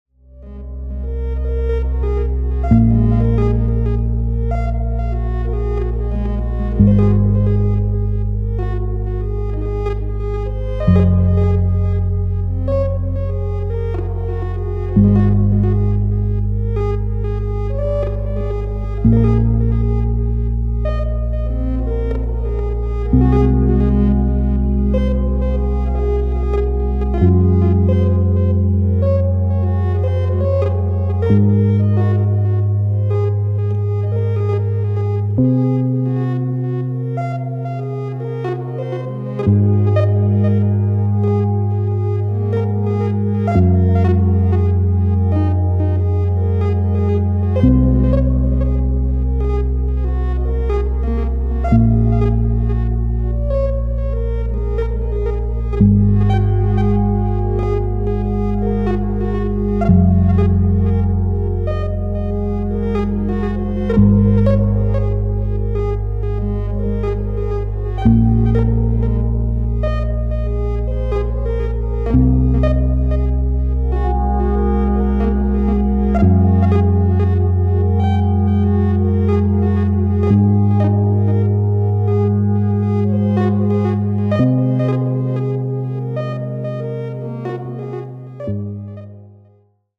floating between ambient, sonic sculpture, and improvisation
Electronix Ambient